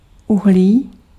Ääntäminen
IPA : /ˈkɑːr.bən/